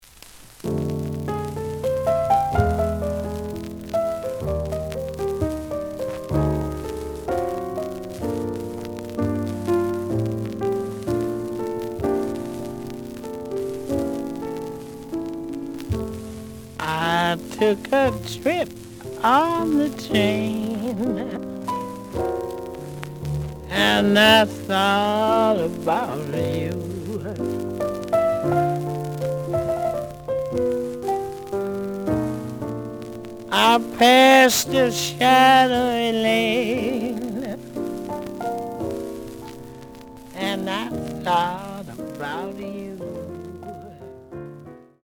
The audio sample is recorded from the actual item.
●Genre: Vocal Jazz
Looks good, but slight noise on both sides.)